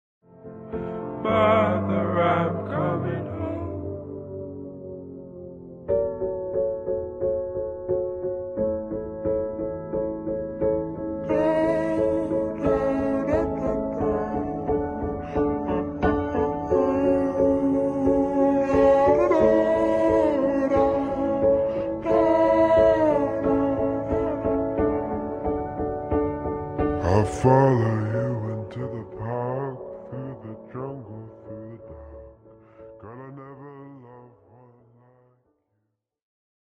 (soft/sad/vent)